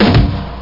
Bonham Snare Kick Sound Effect
Download a high-quality bonham snare kick sound effect.
bonham-snare-kick.mp3